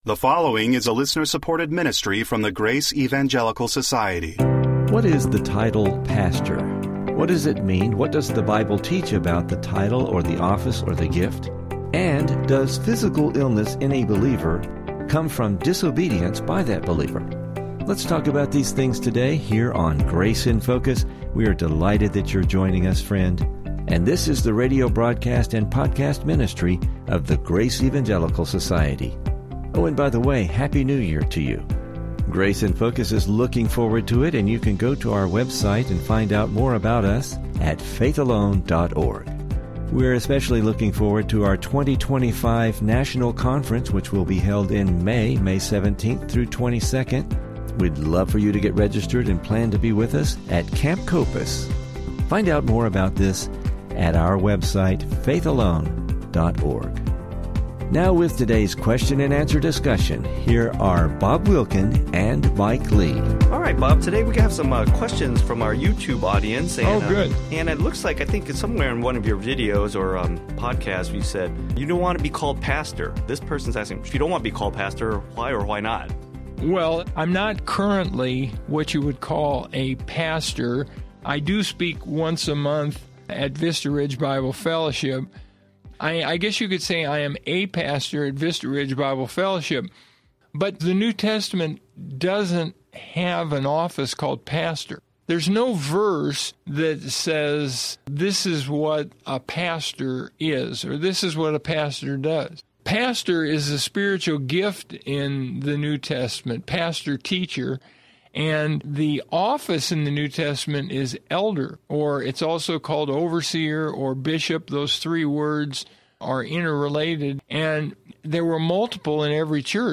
are answering two interesting questions today from our YouTube audience